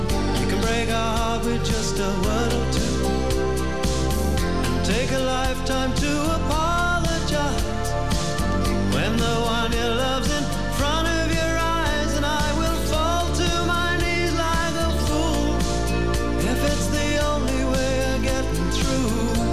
FM-тюнер
К достоинствам TEA5767 можно отнести хорошее качество звука (скачать